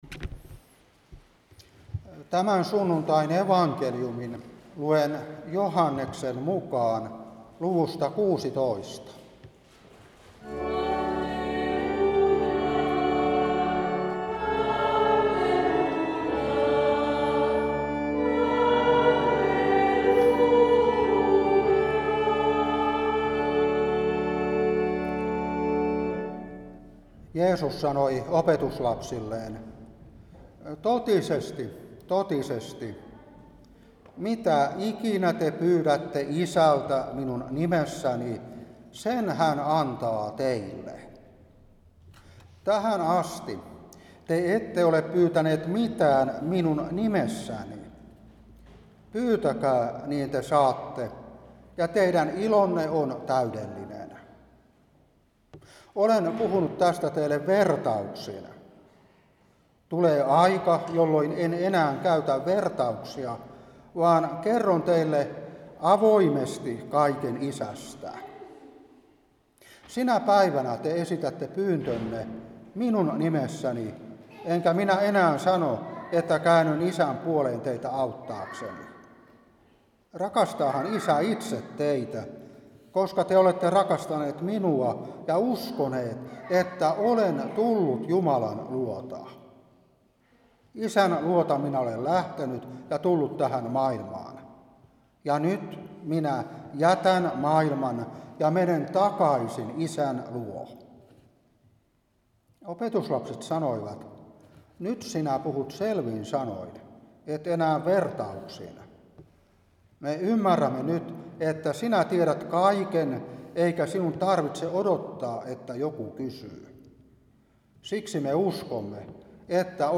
Saarna 2025-5.